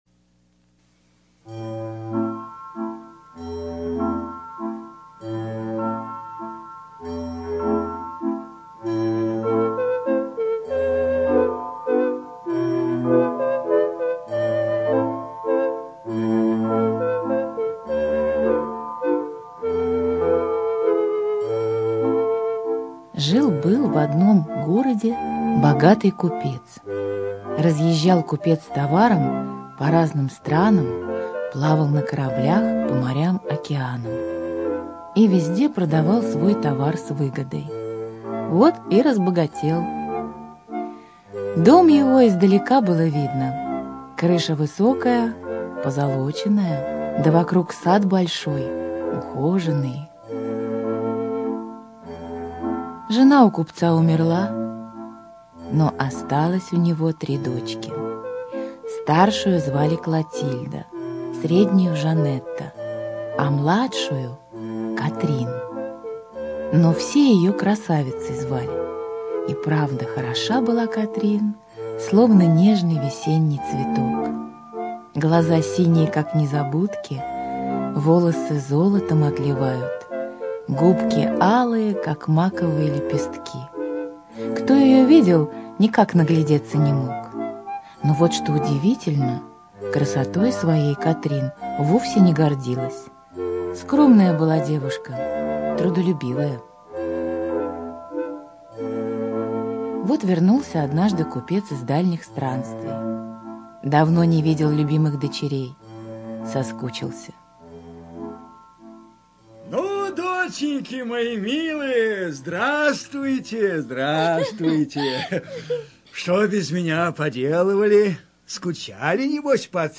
Прослушайте без регистрации аудиокнигу для детей Красавица и Чудовище вместе с вашим ребёнком.
Аудиосказка Красавица и Чудовище слушать онлайн